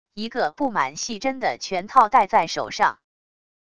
一个布满细针的拳套戴在手上wav音频